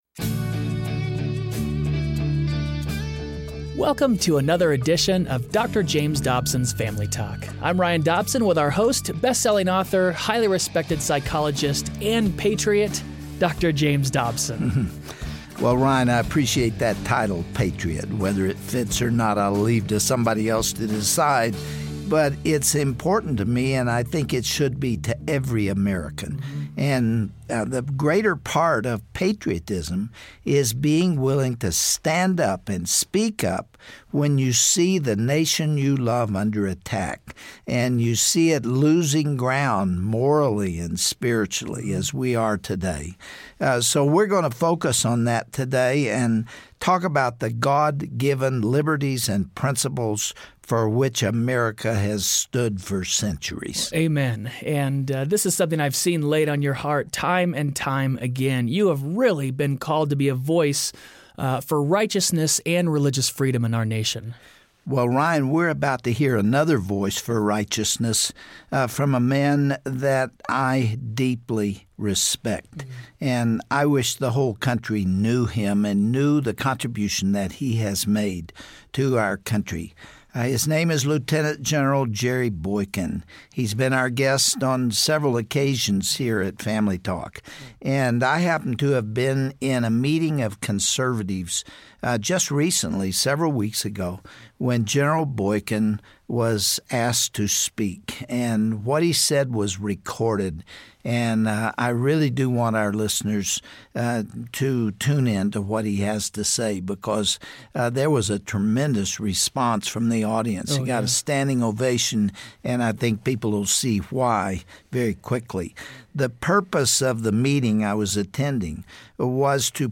Host Dr. James Dobson
Guest(s):Lt. Gen. William "Jerry" Boykin